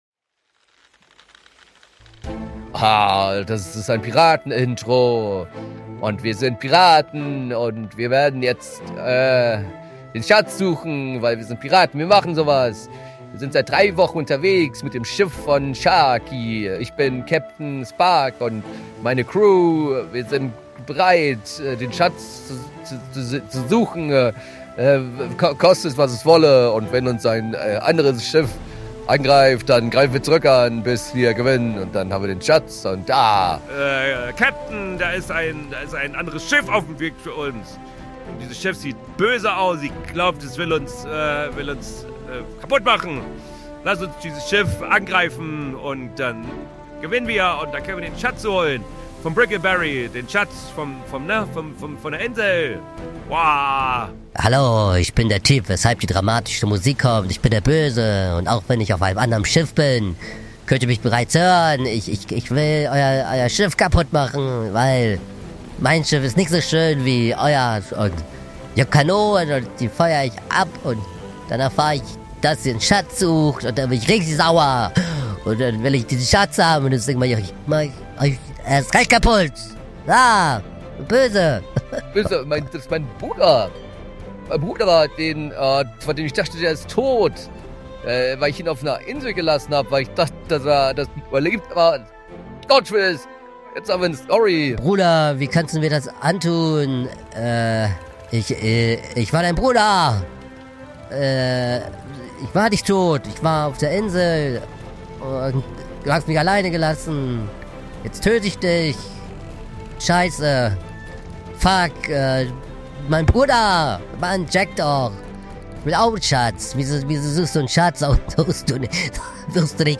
Genres: Comedy , Improv , Leisure